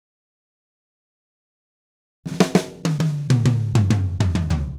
Drumset Fill 06.wav